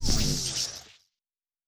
pgs/Assets/Audio/Sci-Fi Sounds/Electric/Shield Device 1 Stop.wav at 7452e70b8c5ad2f7daae623e1a952eb18c9caab4
Shield Device 1 Stop.wav